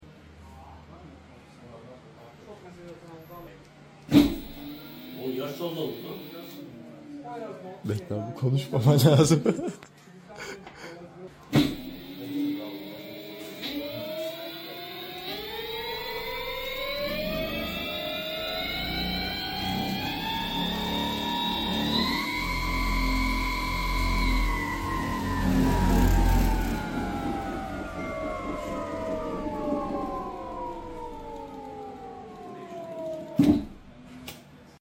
Dualtron Thunder 3 Power And Sound Effects Free Download